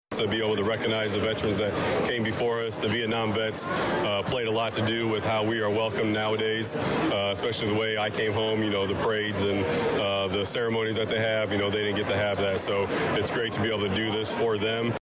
Saturday’s ceremony at Georgetown-Ridge Farm High School was moved indoors due to the wet weather from Friday.